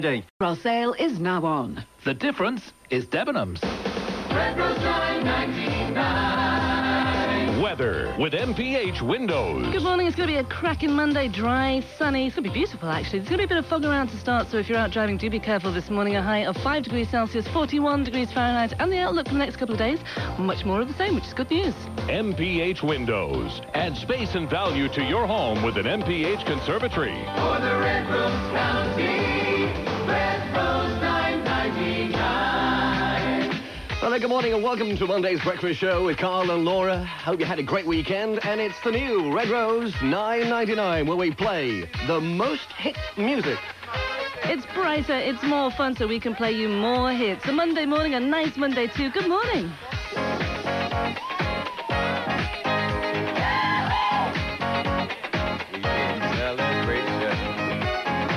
Launch of Red Rose 999 in Preston, Lancashire - Part 2.